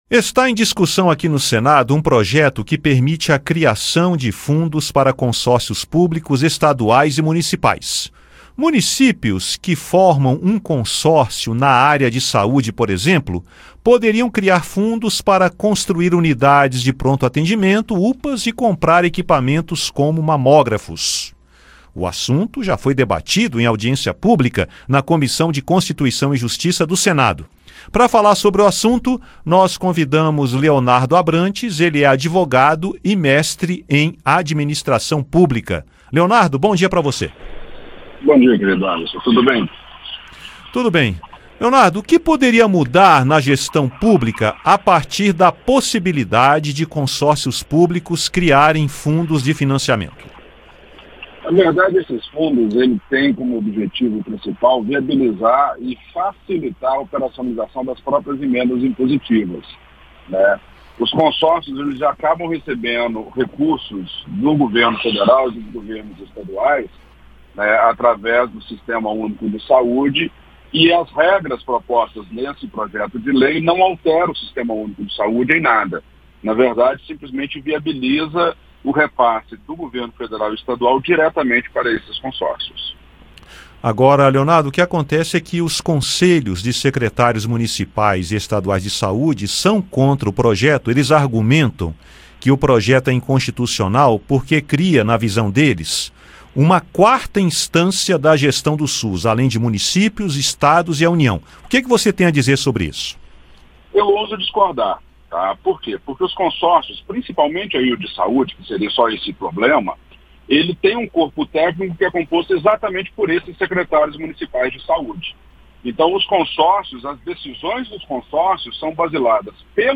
Programa diário com reportagens, entrevistas e prestação de serviços
Advogado comenta possibilidade da criação de fundos para consórcios públicos estaduais e municipais